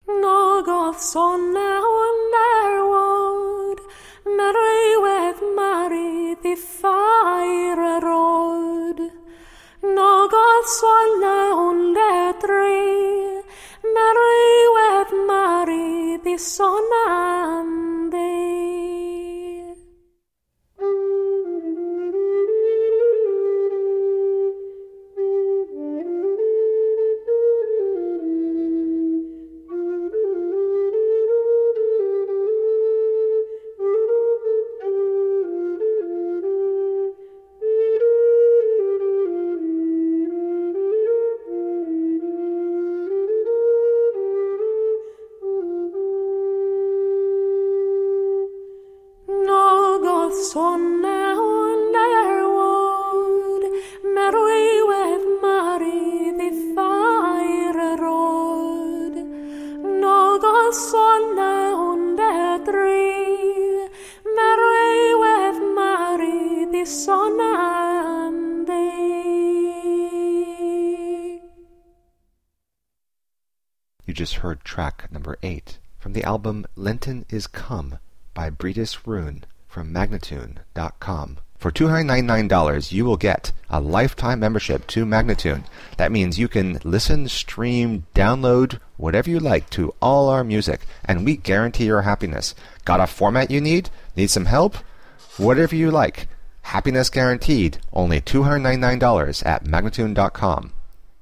13th century medieval english songs.
Classical, Medieval, Classical Singing, Flute, Harp